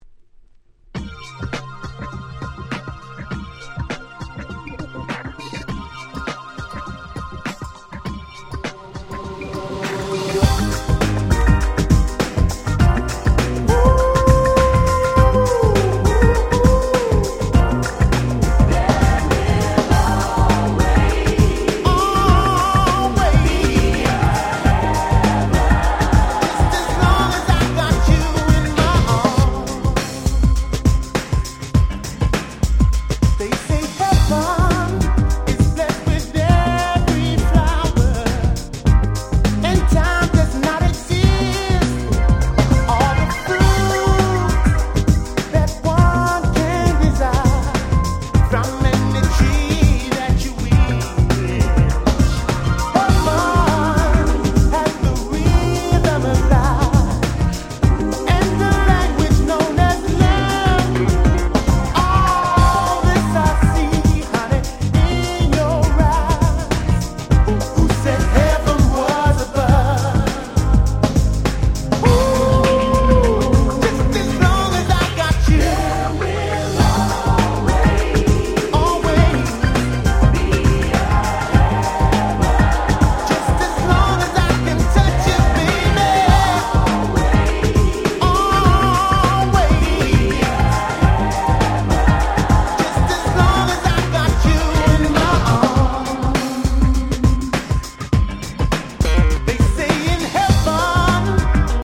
93' Very Nice UK R&B !!
疾走感満載の最高なUK Soulナンバー！！